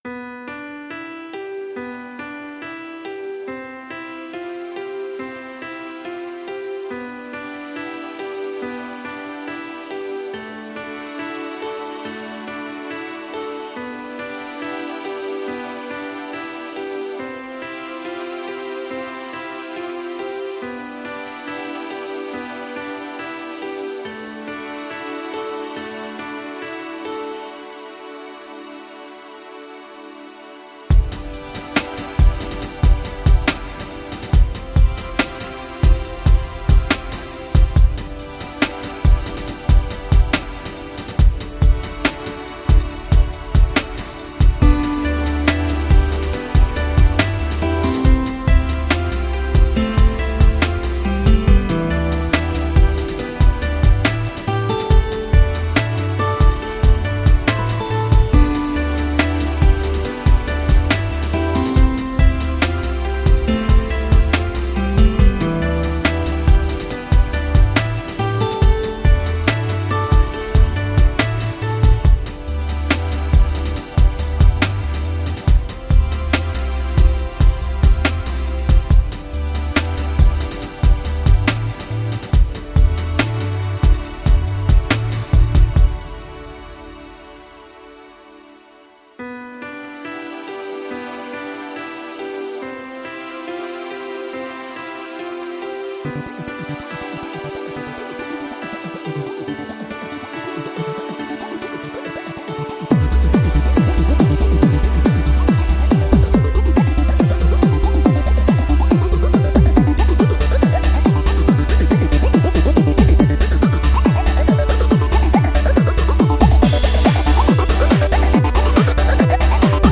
Straddling several electronica genres
Tagged as: Electro Rock, Ambient